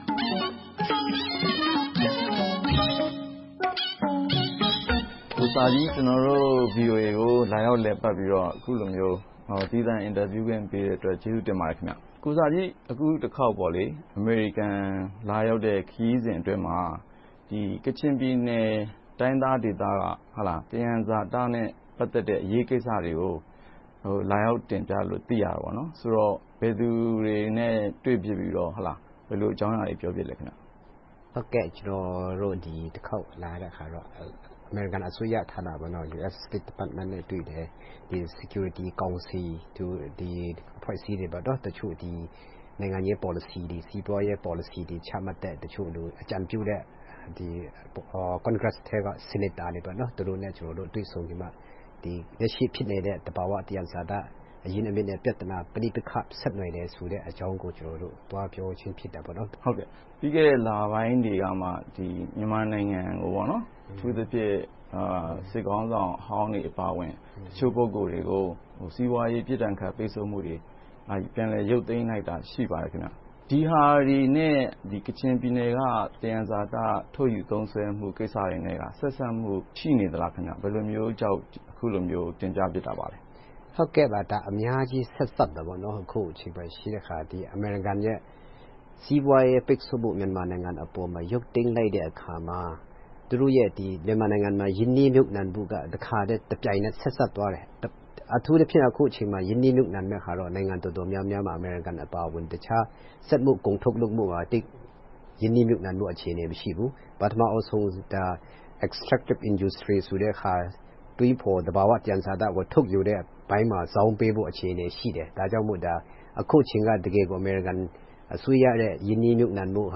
VOA ရုံးချုပ်မှာ
သီးသန့်တွေ့ဆုံမေးမြန်းထားပါတယ်။